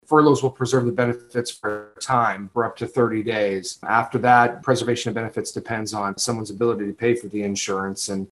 K-State administrators discussed the possibility of implementing furloughs during a virtual town hall with university faculty and staff Tuesday.